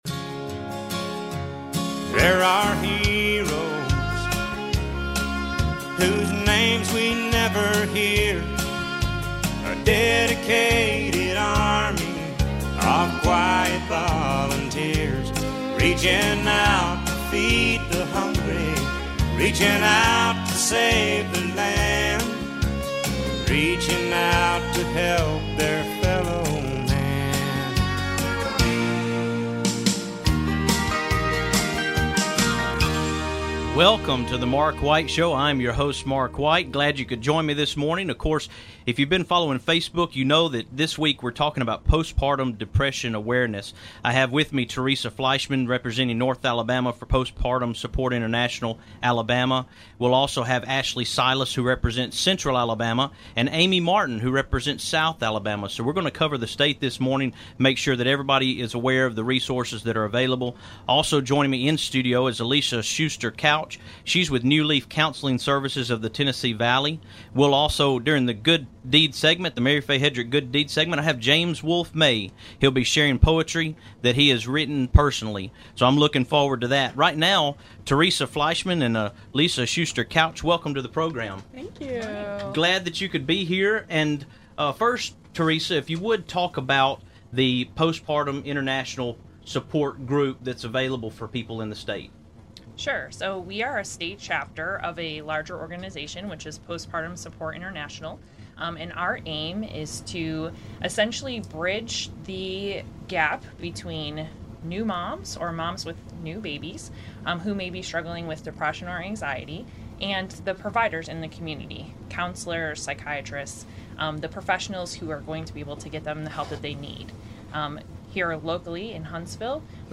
We'll be having a conversation about the commitment of their organization to promote awareness, prevention and treatment of mental health issues related to childbearing.